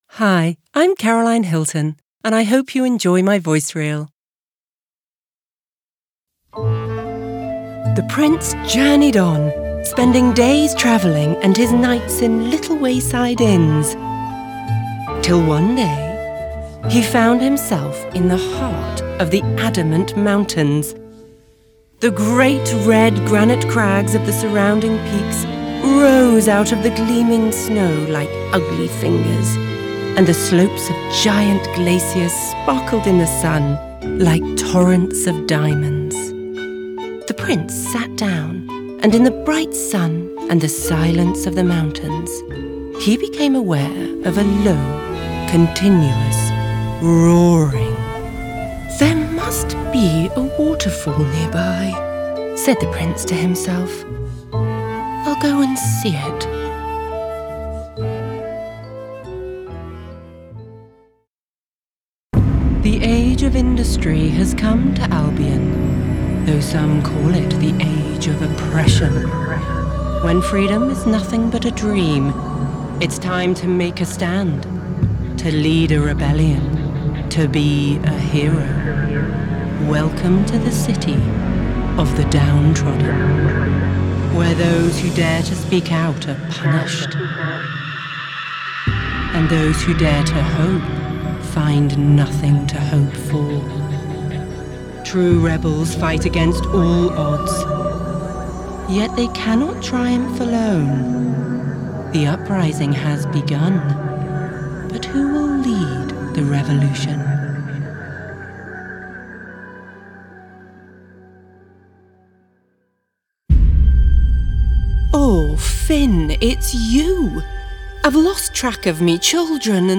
Sound Bites
Gaming / Animation Reel